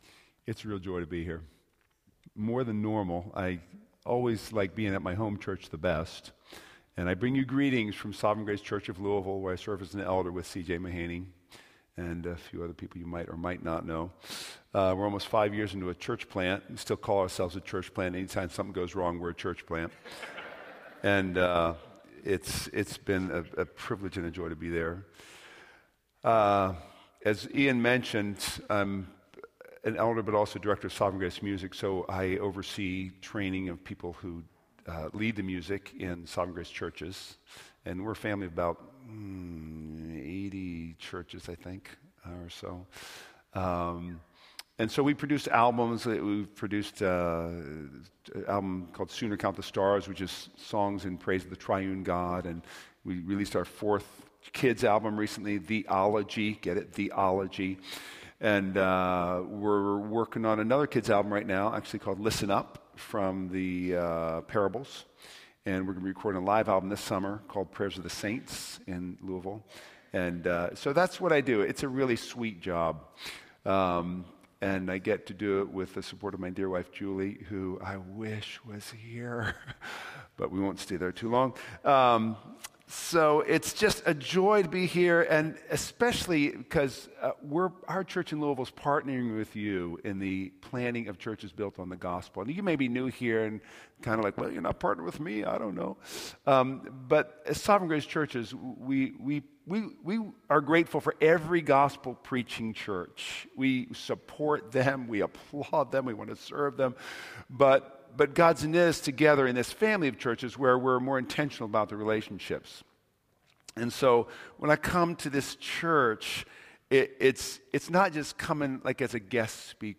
A sermon from the series "Stand Alone Sermons."